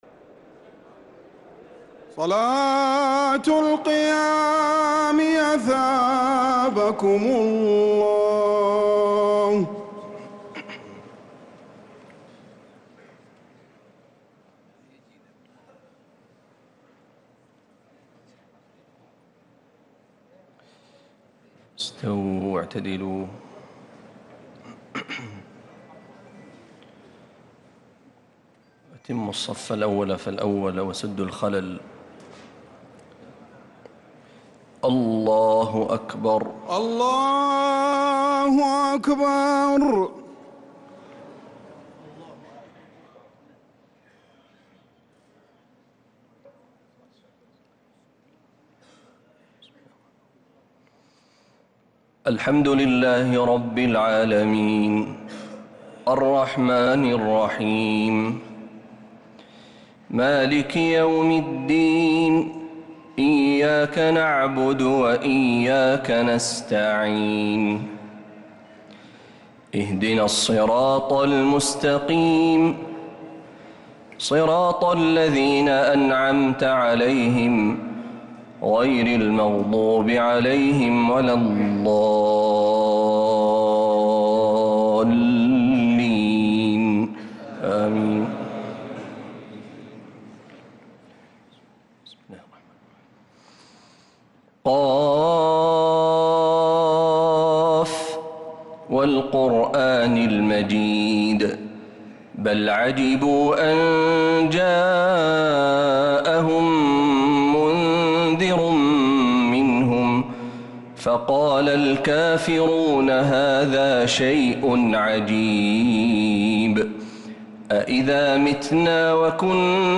تراويح ليلة 27 رمضان 1446هـ من سورة ق الى سورة القمر (1-3) | Taraweeh 27th night Ramadan 1446H Surah Qaf to Al-Qamar > تراويح الحرم النبوي عام 1446 🕌 > التراويح - تلاوات الحرمين